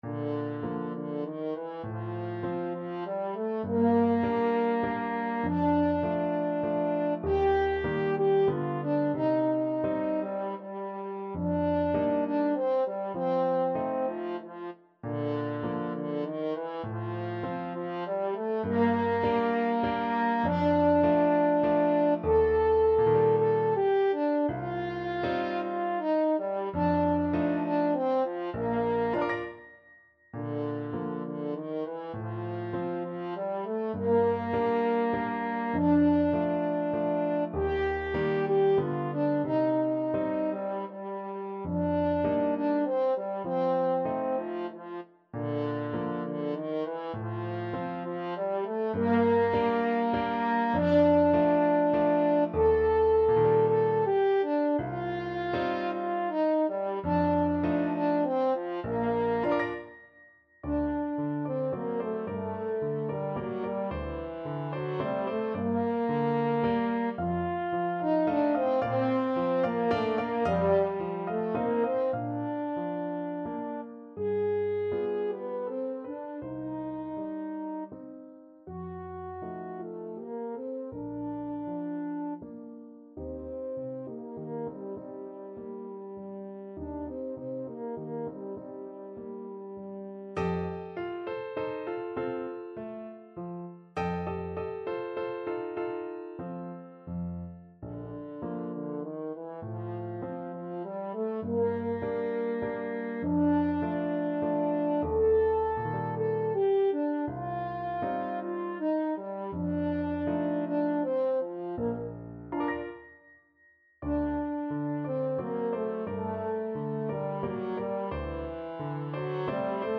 Classical Merikanto, Oskar Valse lente, Op.33 French Horn version
French Horn
Bb major (Sounding Pitch) F major (French Horn in F) (View more Bb major Music for French Horn )
3/4 (View more 3/4 Music)
~ = 100 Tranquillamente
D4-A5
Classical (View more Classical French Horn Music)